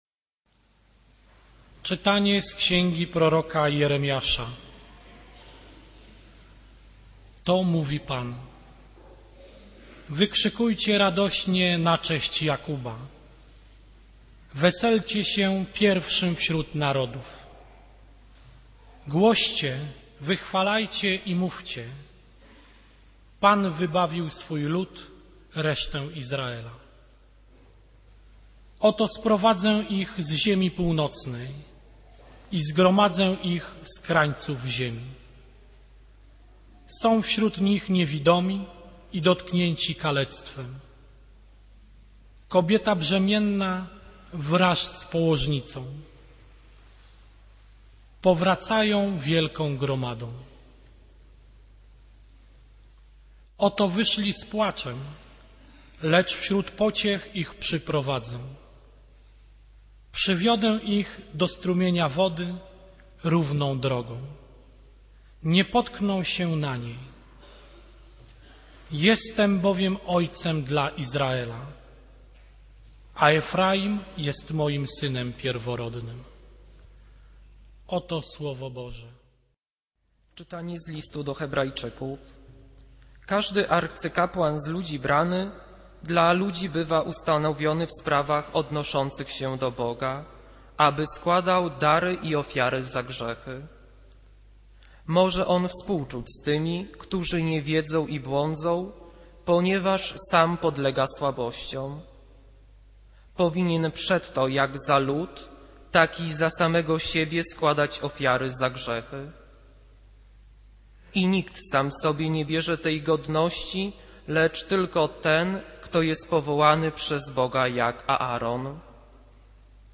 Kazanie z 25 października 2009r.